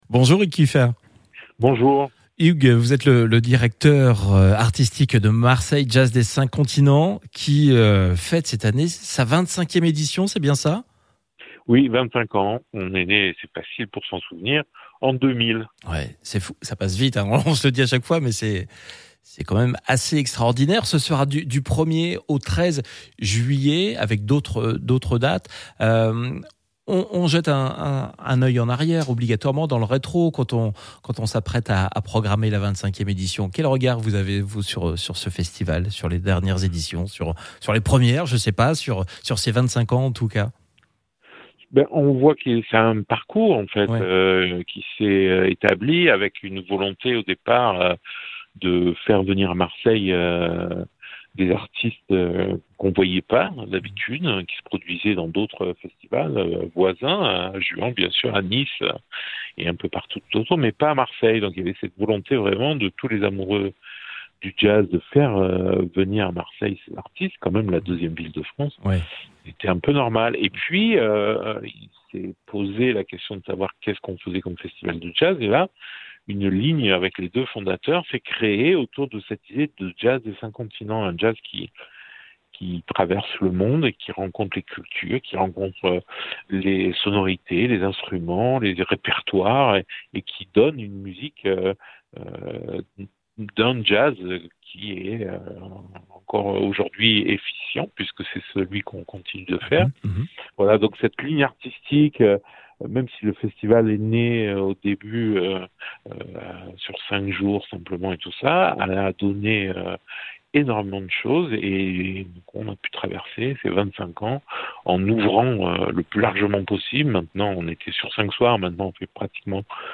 Une interview pour Jazz Radio